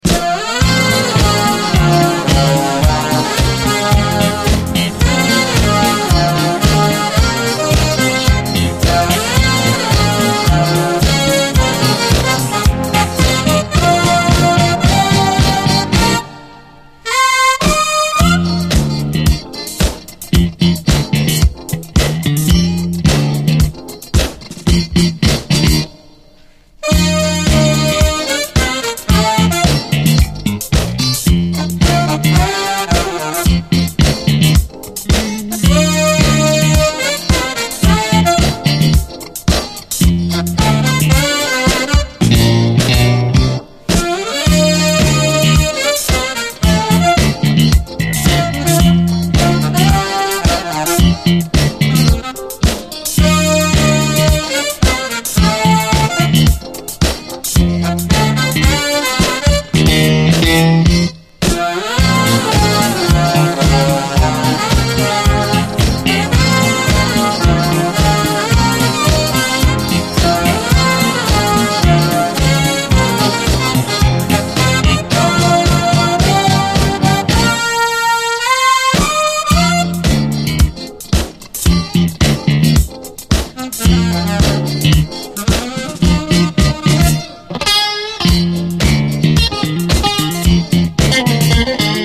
SOUL, 70's～ SOUL, DISCO
メロディアスかつ爽やかに展開するマイナー・ブリット・ファンク！